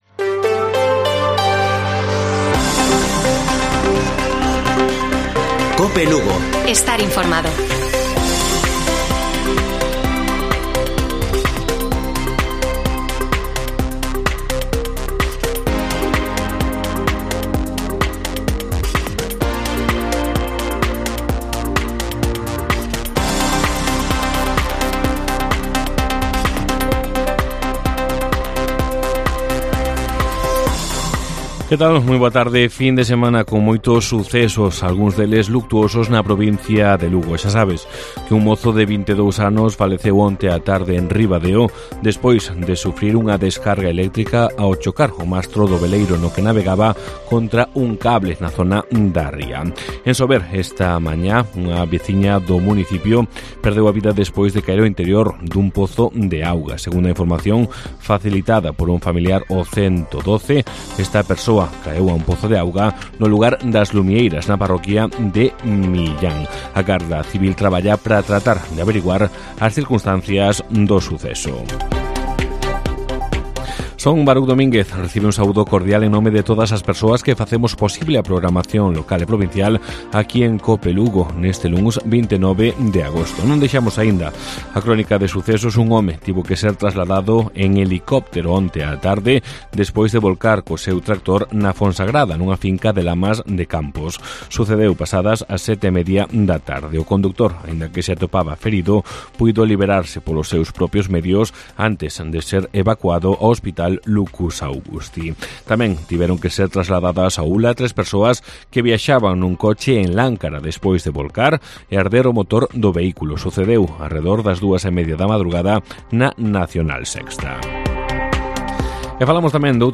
Informativo Mediodía de Cope Lugo. 29 DE AGOSTO. 14:20 horas